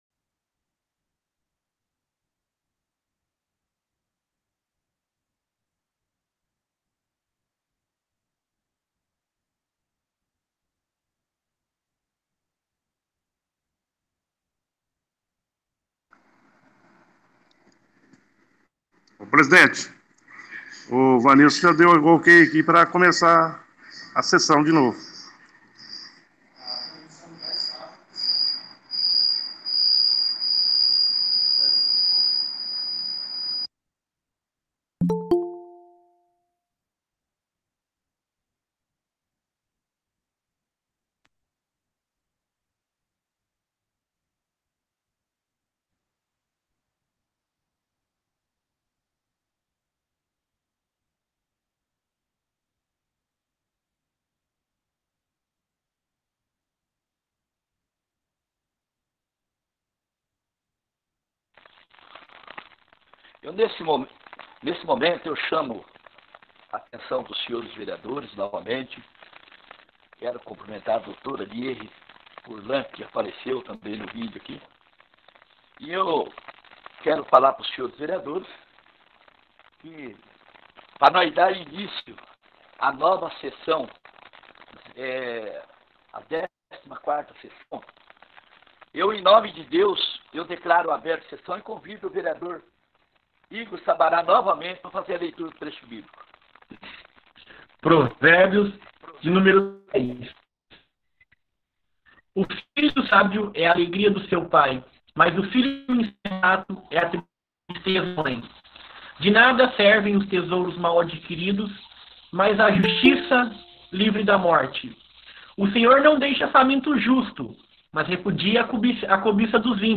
14ª Reunião Extraordinária 12-09-20.mp3